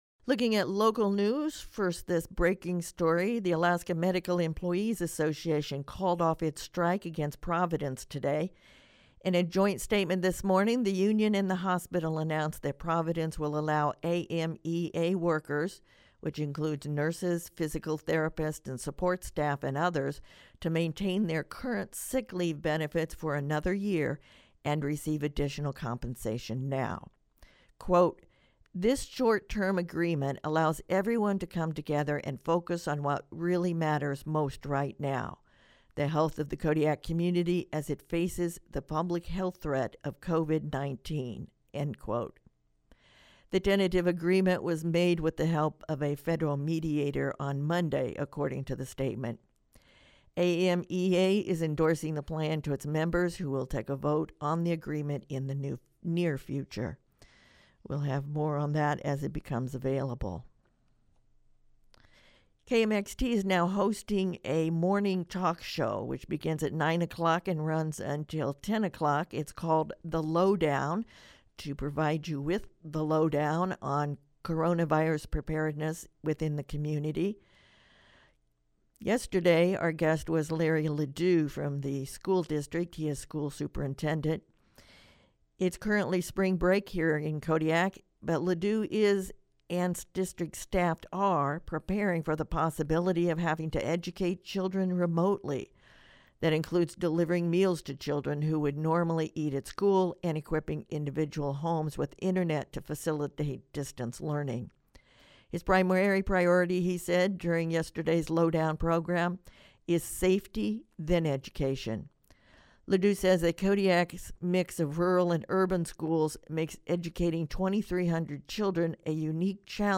Newscast — Tuesday, March 17, 2020